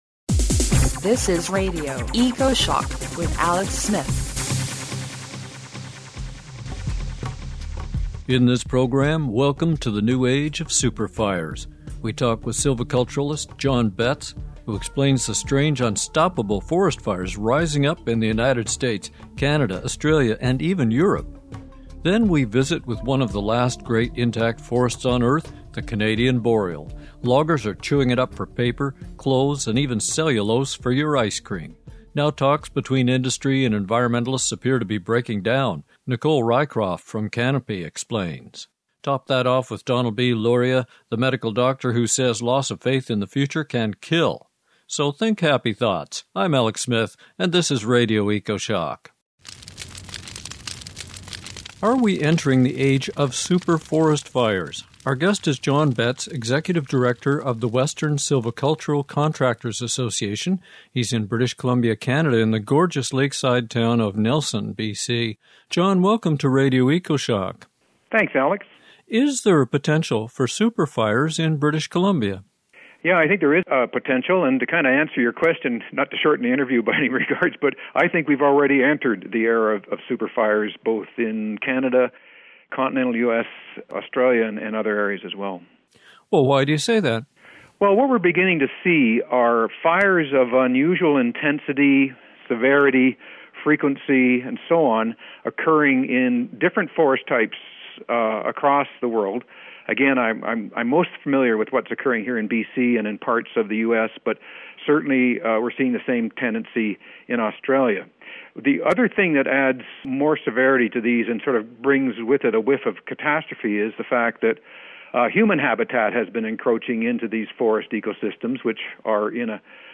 Listen to/download this Radio Ecoshock Show in CD Quality (56 MB) or Lo-Fi (14 MB)